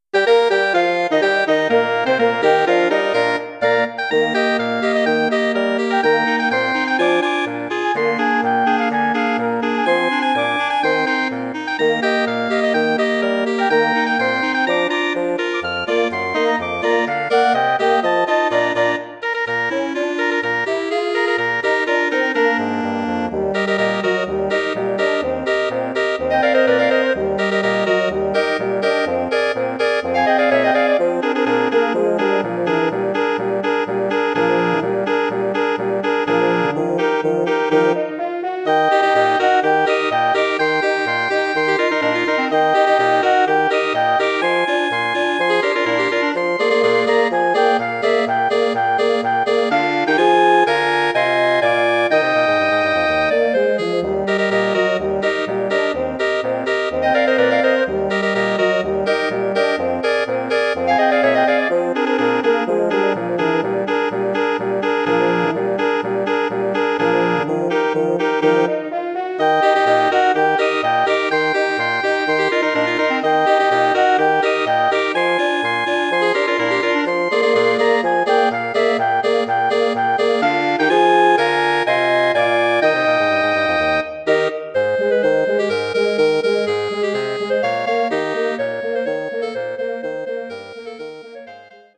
Bearbeitung für Bläserquintett
Besetzung: Flöte, Oboe, Klarinette, Horn, Fagott
Arrangement for wind quintet
Instrumentation: flute, oboe, clarinet, horn, bassoon